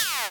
cacoplushie_despawn.ogg